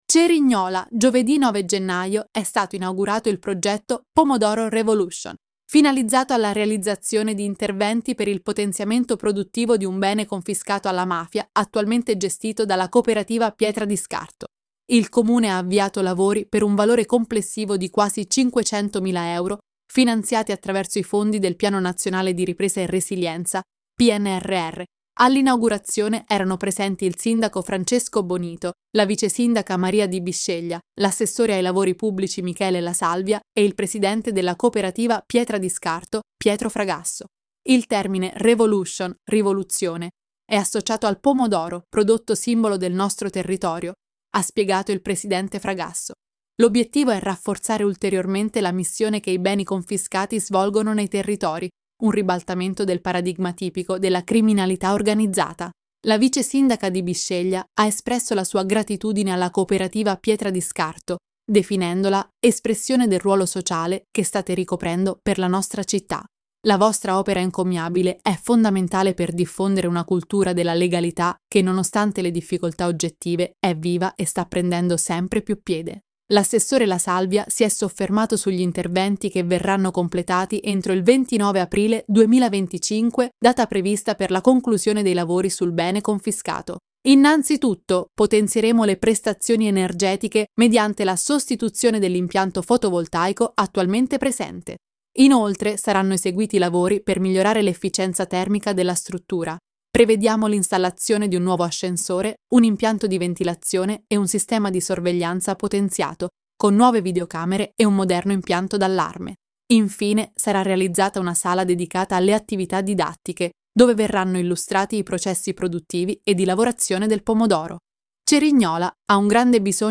Audiolettura)